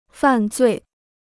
犯罪 (fàn zuì) Free Chinese Dictionary